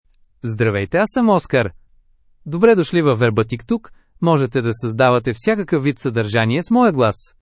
OscarMale Bulgarian AI voice
Oscar is a male AI voice for Bulgarian (Bulgaria).
Voice sample
Listen to Oscar's male Bulgarian voice.
Male